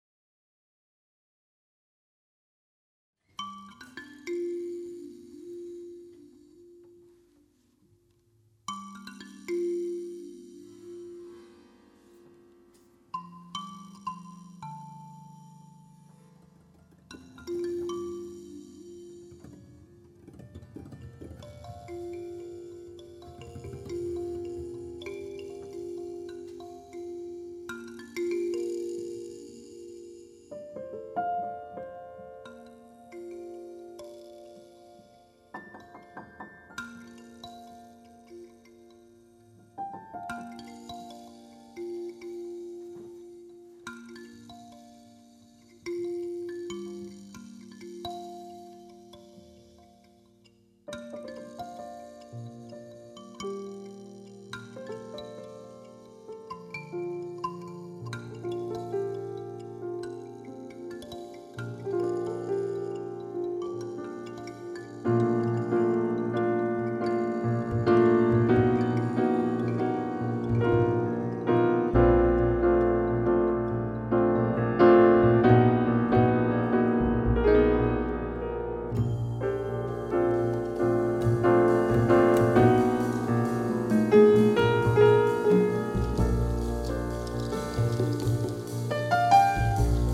klavir
godalni kvartet
kontrabas
trobenta
bobni